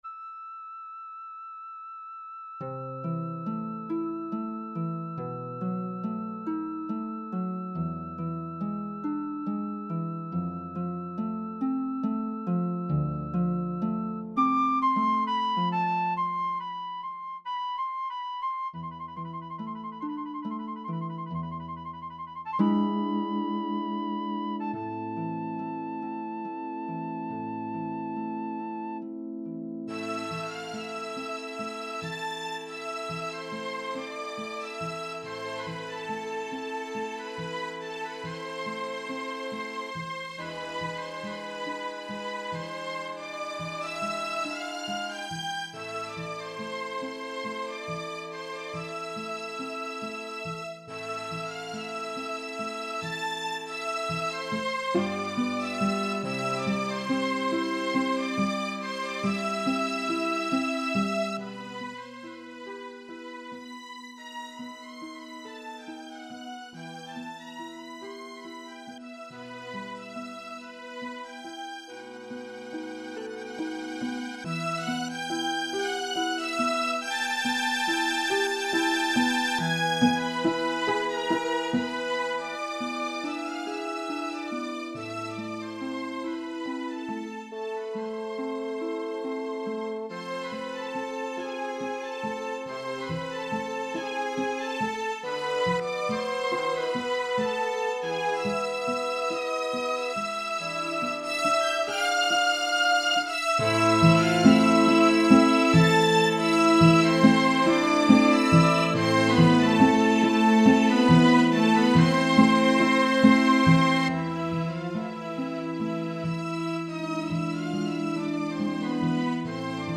Flute
Oboe
Clarinet in A
Bassoon
Horn in F
Harp
Violin1
Violin2
Viola
Violincello
Doublebase